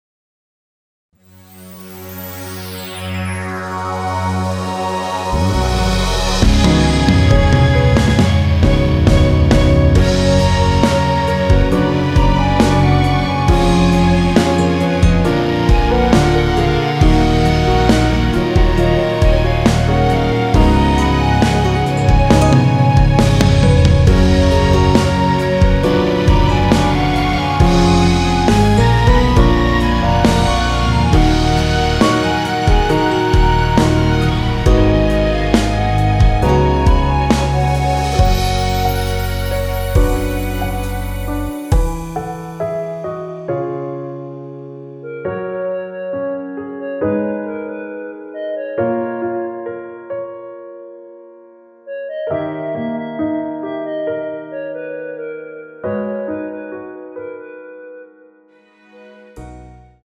원키에서(+4)올린 멜로디 포함된 MR입니다.(미리듣기 확인)
F#
앞부분30초, 뒷부분30초씩 편집해서 올려 드리고 있습니다.
중간에 음이 끈어지고 다시 나오는 이유는